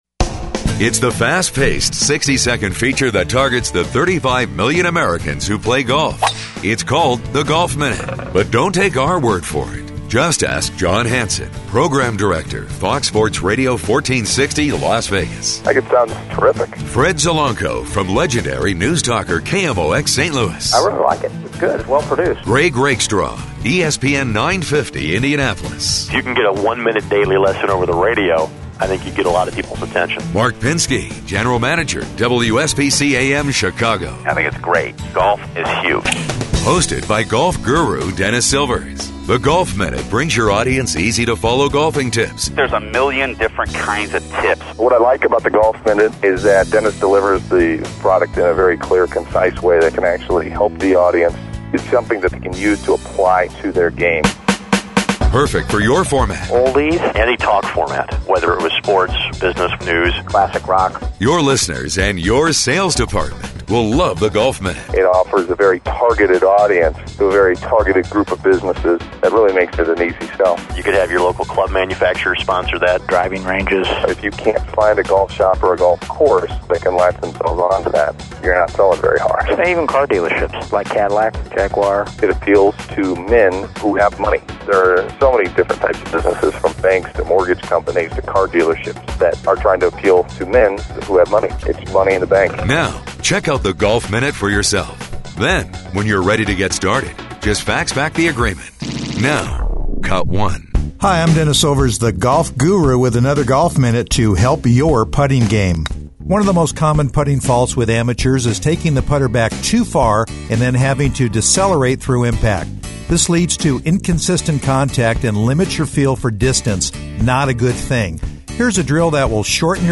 These outstanding daily 60 second features are delivered year around.